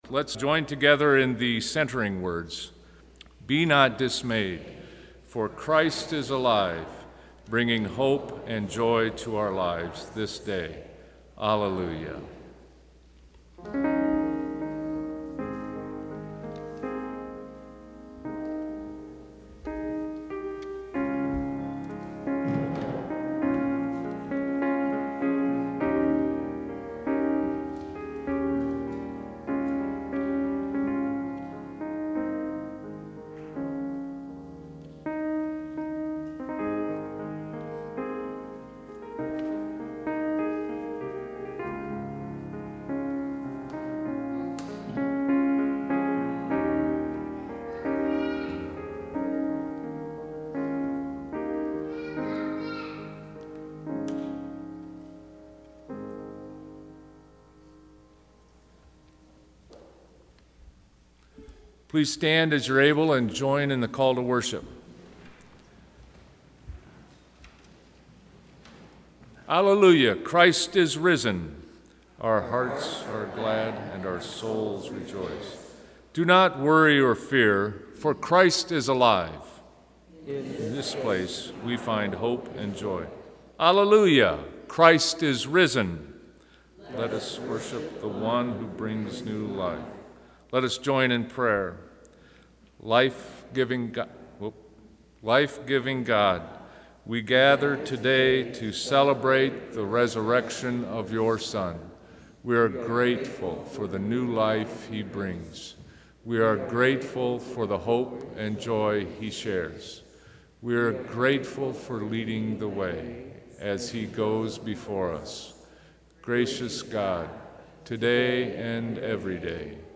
First United Methodist Church Sermon Audio Files